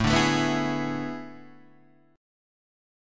AM7sus2sus4 chord